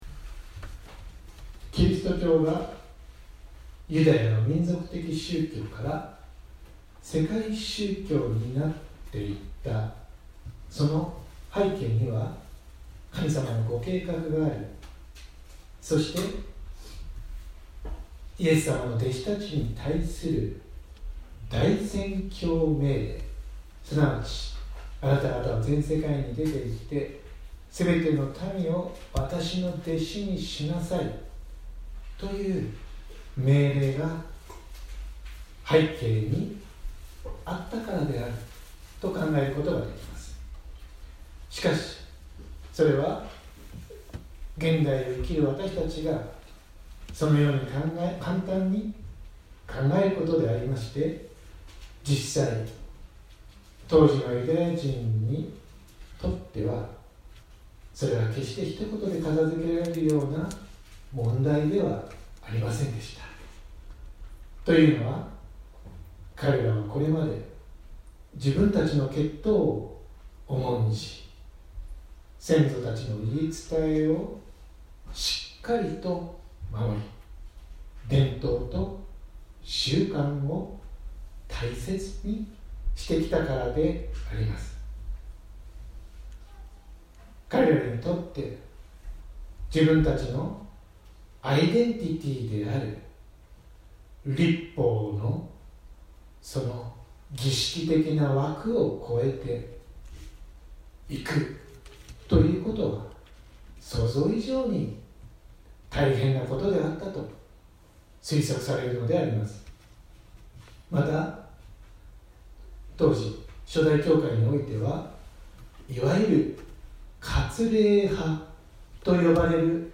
2019年02月24日朝の礼拝「読者よ、悟れ 읽는 자는 깨달을진저 」せんげん台教会
音声ファイル 礼拝説教を録音した音声ファイルを公開しています。